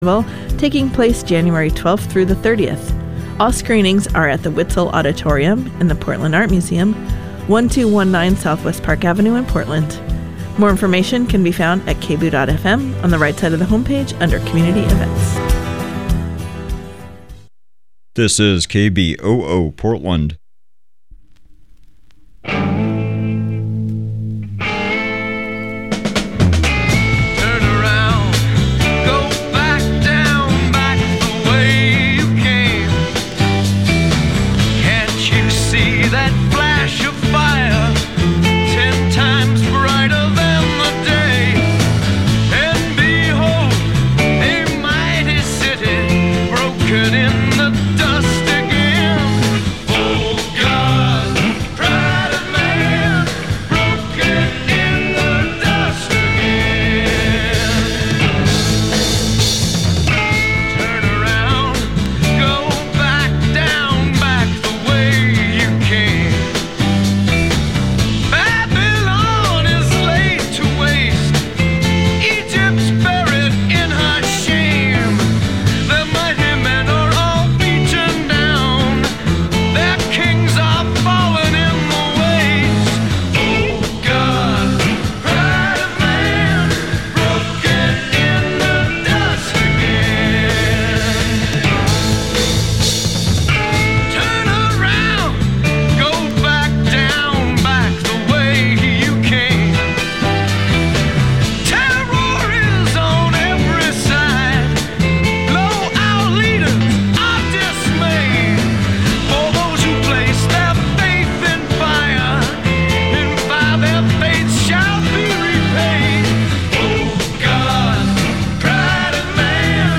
Wednesday Talk Radio